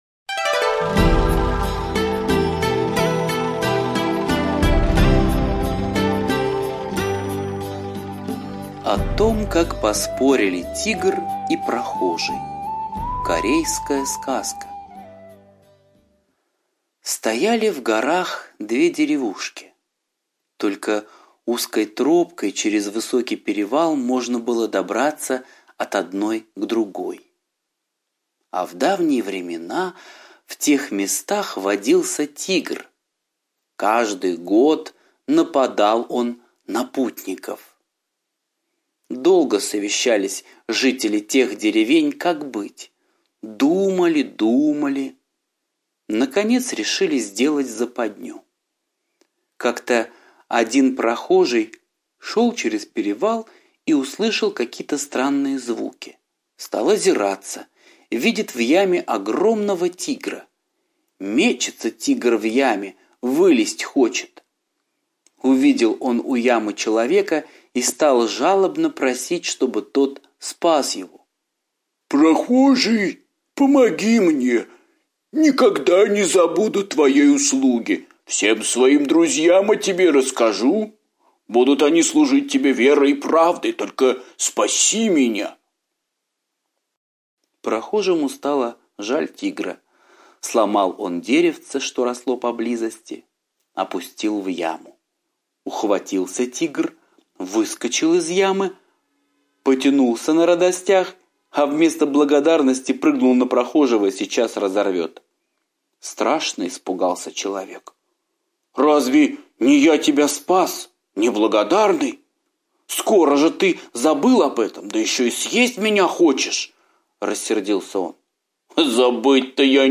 О том, как поспорили Тигр и прохожий - восточная аудиосказка - слушать онлайн